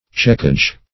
Search Result for " checkage" : The Collaborative International Dictionary of English v.0.48: checkage \check"age\ (ch[e^]k"[asl]j), n. 1. The act of checking; as, the checkage of a name or of an item in a list.